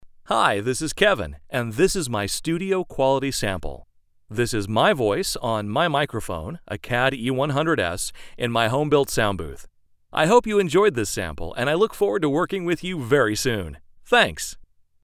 Male
Yng Adult (18-29), Adult (30-50)
Studio Quality Sample
Natural Friendly Relateable
Words that describe my voice are friendly, relatable, energetic.
Male Voice Over Talent